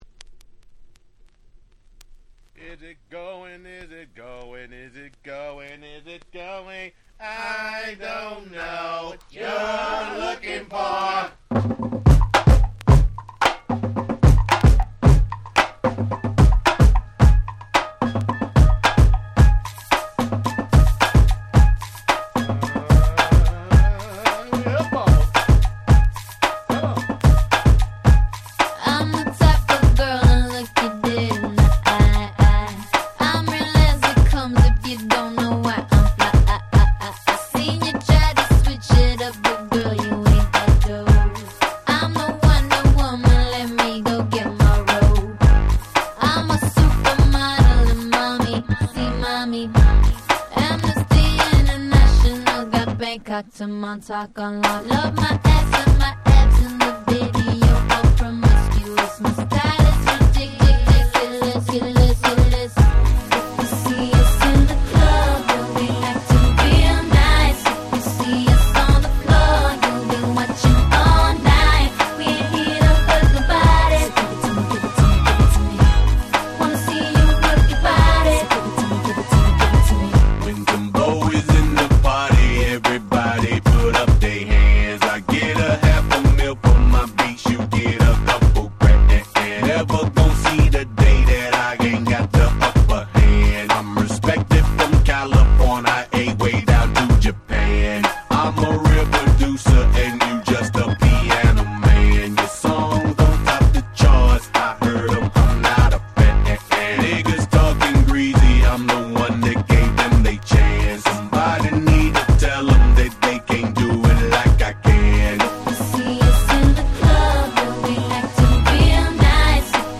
07' Super Hit R&B !!